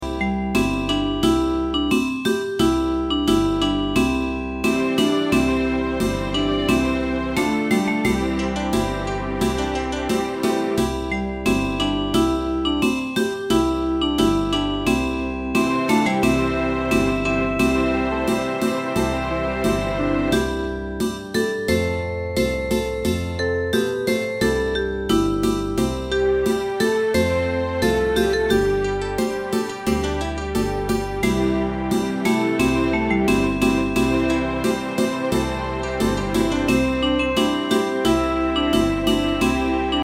大正琴の「楽譜、練習用の音」データのセットをダウンロードで『すぐに』お届け！
カテゴリー: アンサンブル（合奏） .
歌謡曲・演歌